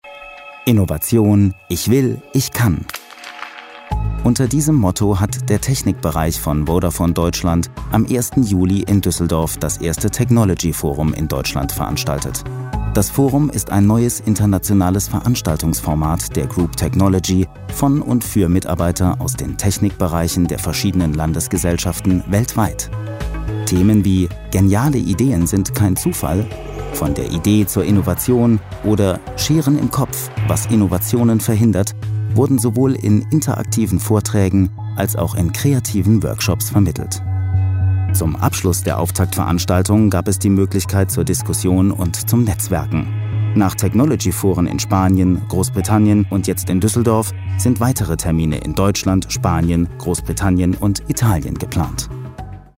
Sprecher Imagefilm, Dokumentarfilm, Off Sprecher, Voice over, Werbesprecher, Feature, Hörbuch, Hörspiel, Point of Sale, DMAX, Vodafone, Synchronsprecher, Sonore Stimme, Arte, 3SAT
Sprechprobe: Industrie (Muttersprache):